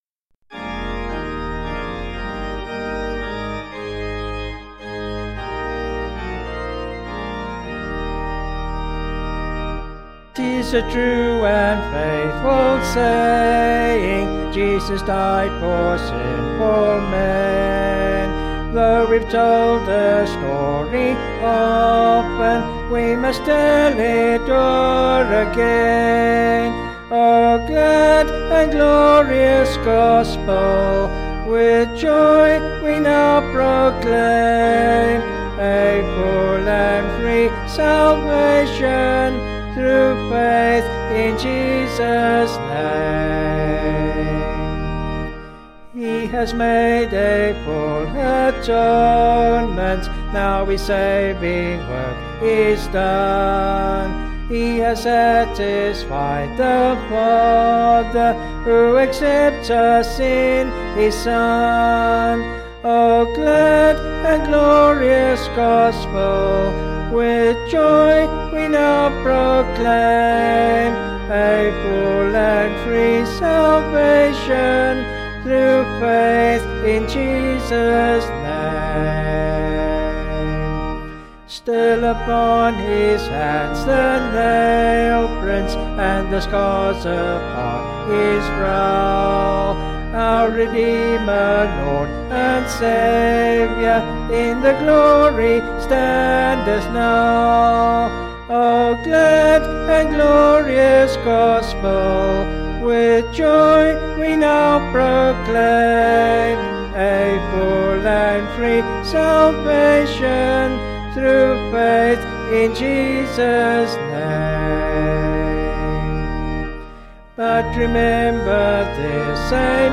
Vocals and Organ   264.5kb Sung Lyrics